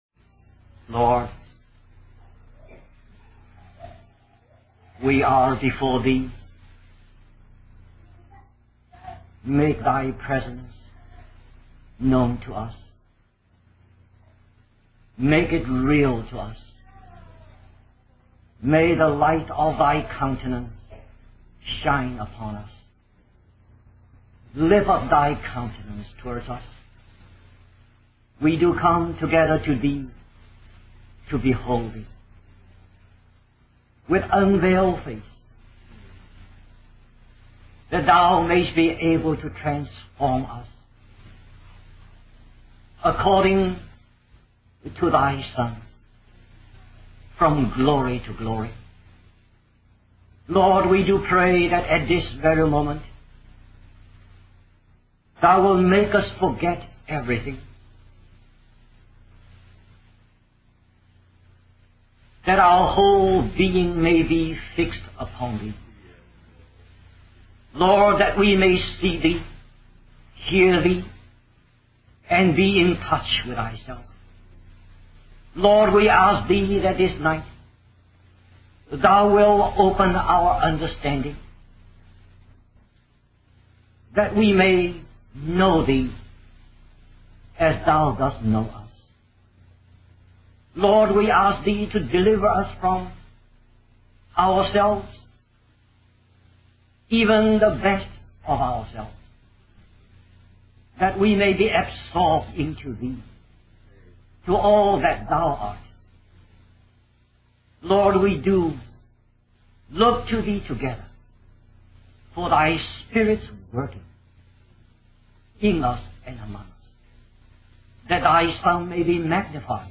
Wabanna (Atlantic States Christian Convocation)